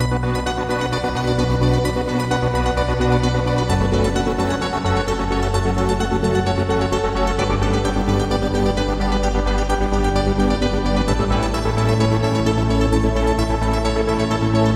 Trance Piano 130 Bpm
Tag: 130 bpm Trance Loops Piano Loops 2.48 MB wav Key : Unknown